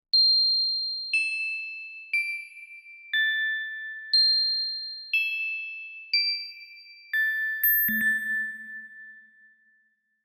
发条钟
描述：铃声
Tag: 120 bpm Hip Hop Loops Percussion Loops 1.72 MB wav Key : Unknown